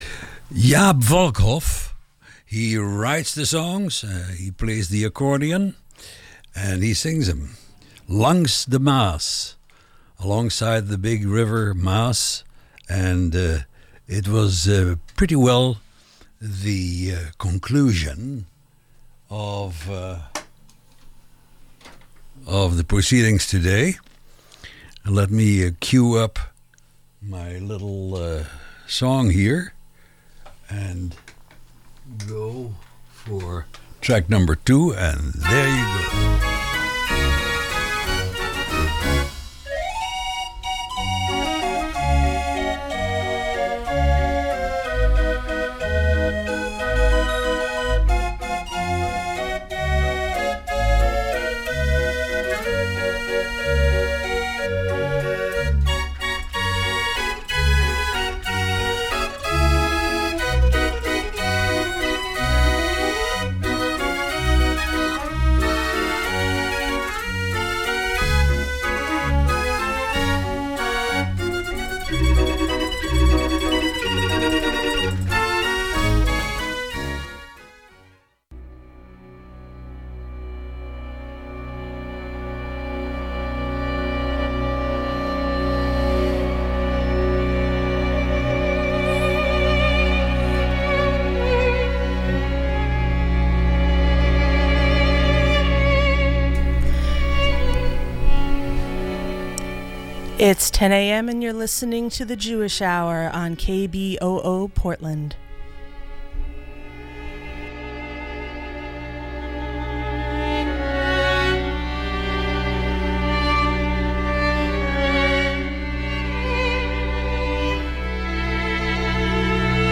featuring live interviews and music